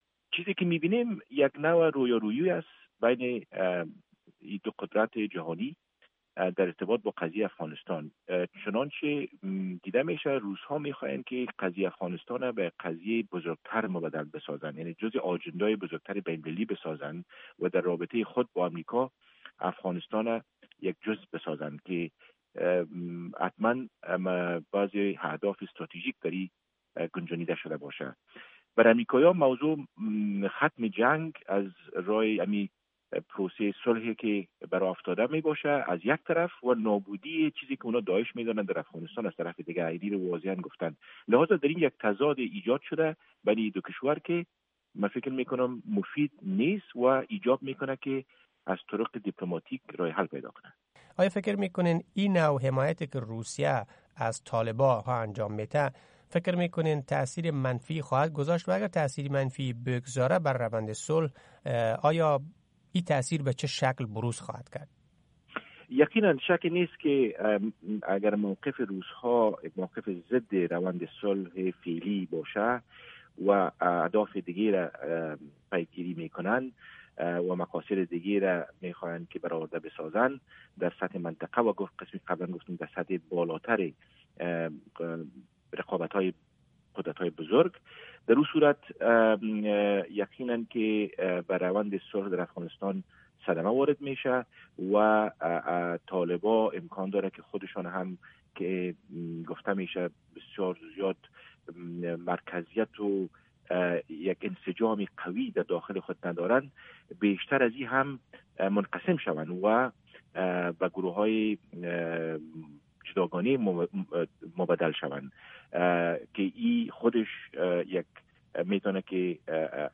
مصاحبۀ کامل صدای امریکا را با عمر صمد، دیپلومات پیشن افغانستان را در اینجا بشنوید